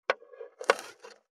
493厨房,台所,野菜切る,咀嚼音,ナイフ,調理音,まな板の上,
効果音